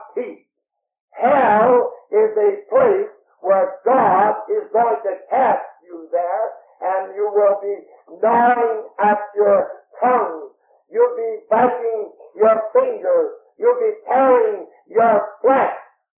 The cassette tape
With some thumping of the bible, and some more shouting, he explains “you accept Jesus Christ as your savior.”
Then he pauses, to let that sink in and then goes on in a softer voice and explains further and gives his own personal story about accepting Jesus Christ as his savior.